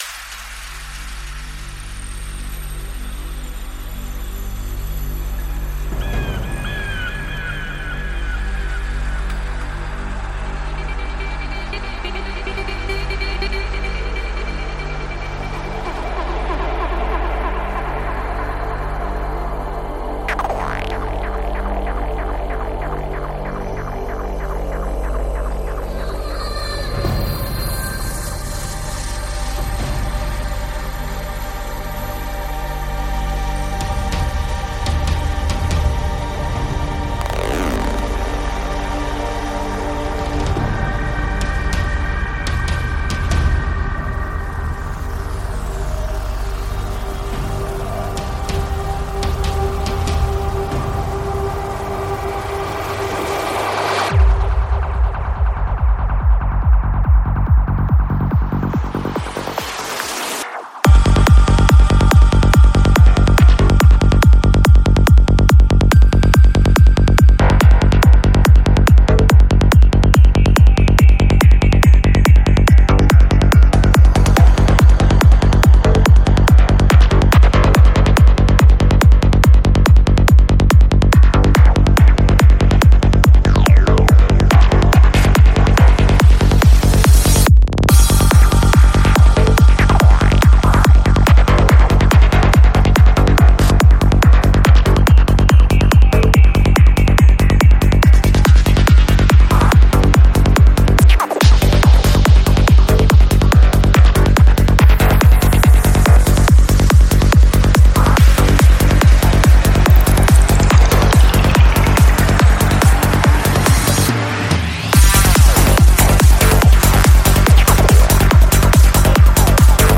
Жанр: Psy Trance